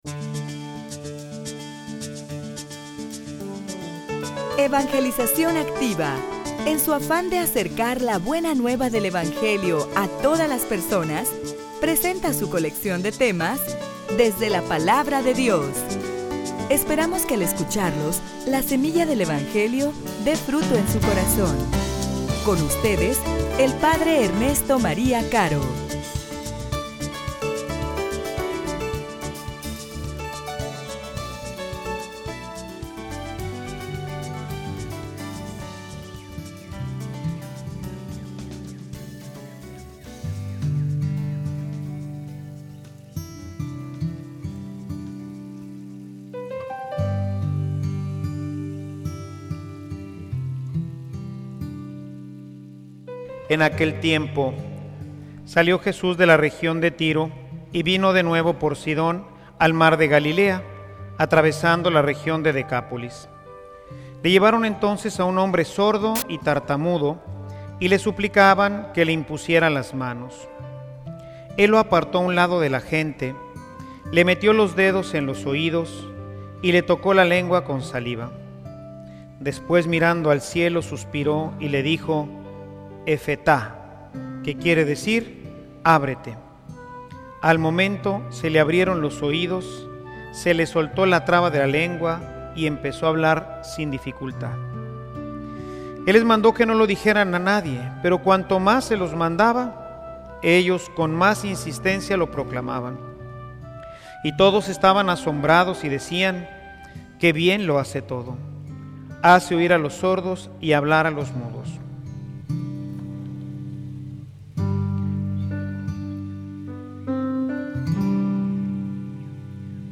homilia_El_Evangelio_Camino_seguro_al_Reino.mp3